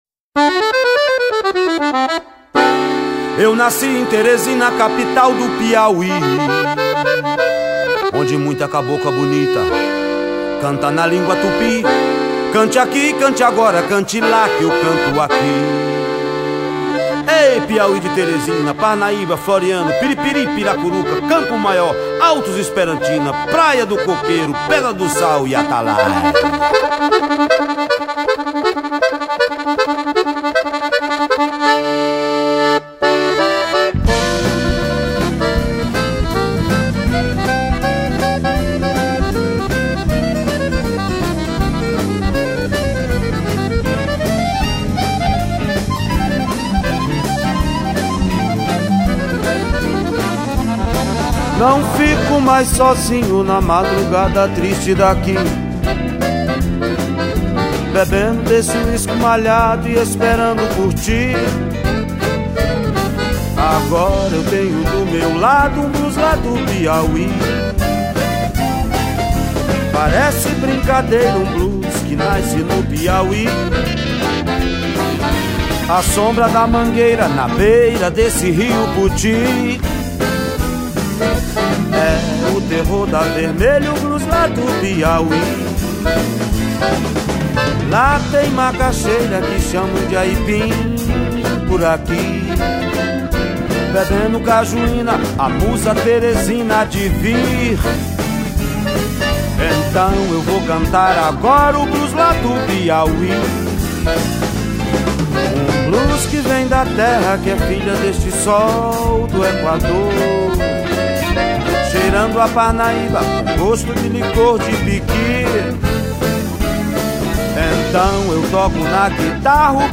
Guitarra, Violao Acústico 6, Violão Elétrico